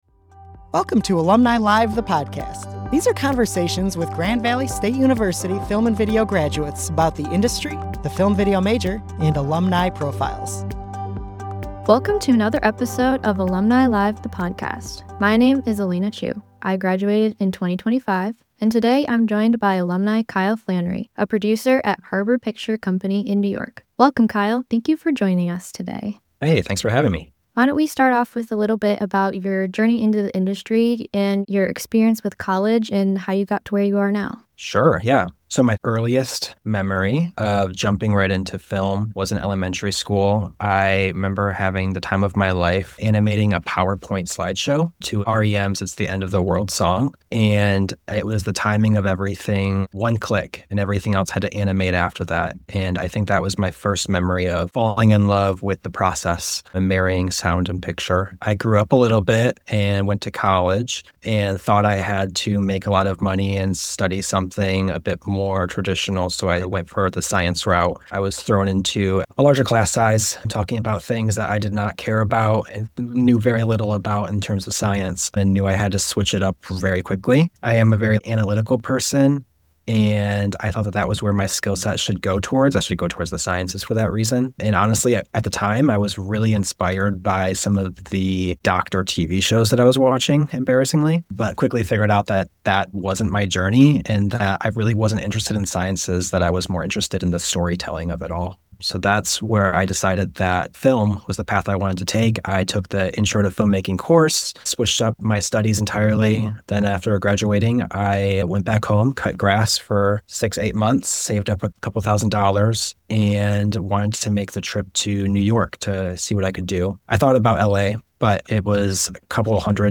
Producing for Post-Production - A Conversation